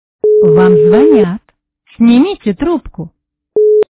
» Звуки » звуки Телефонов » Вам звонят - Снимите трубку
При прослушивании Вам звонят - Снимите трубку качество понижено и присутствуют гудки.